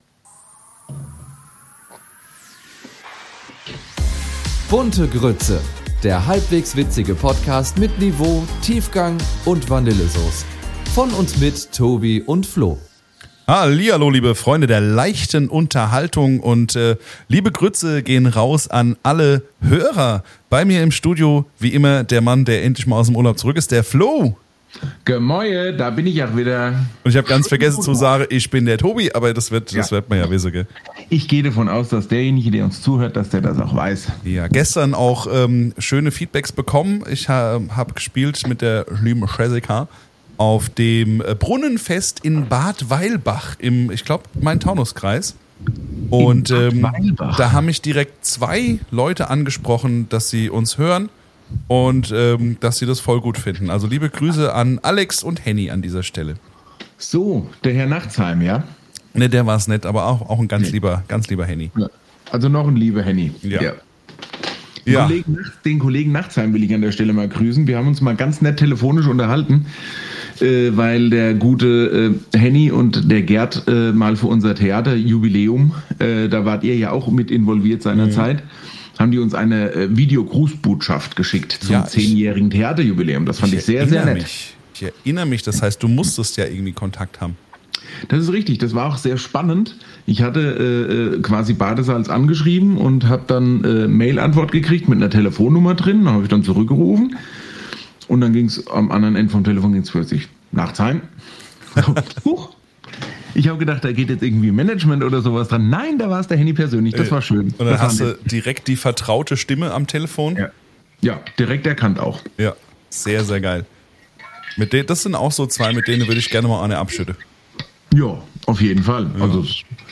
Hessisch